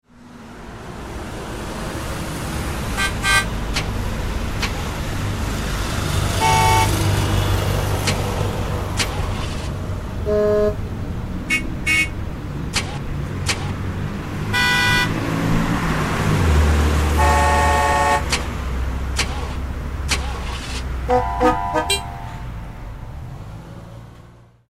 Therefore, this crash course seeks to: 1) rethink and clarify linguistic terminology and methodologies while adapting these to musical analysis; 2) propose a taxonomy that helps identify the features that contribute to the perception of narrative qualities in music; 3) lay down an inter-disciplinary and inter-analytical approach applicable to musique concrète.
These phonological characteristics have the potential to build a fictional space in which the narrative plot will develop [1] In example 1, numerous sounds frame the fictional space of Klang, Kar und Melodie: volume provides the listener-to-source distance, panning locates sounds on a left-right axis, and the lack of reverb portrays an open rather than closed space [2].